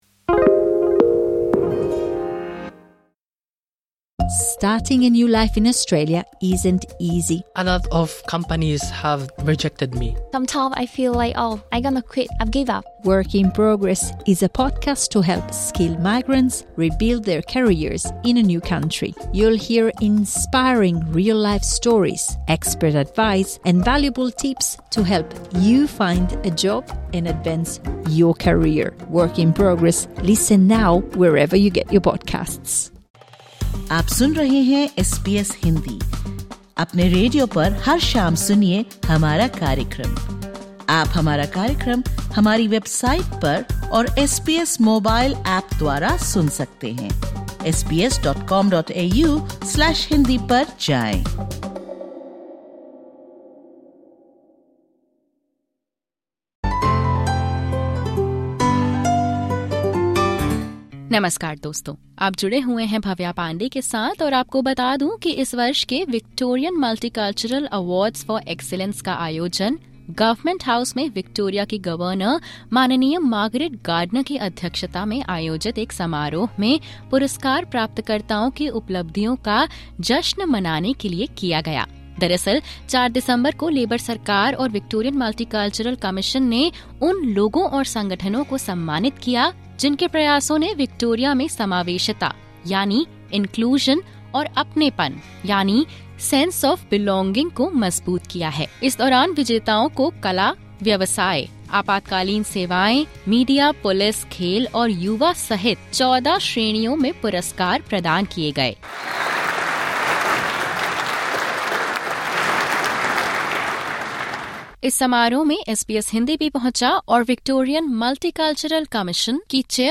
Amid the celebrations, SBS Hindi spoke with South Asian winners who are driving change and shaping the future of their communities through dedicated service and social cohesion Disclaimer: The views expressed in this podcast are that of the interviewees.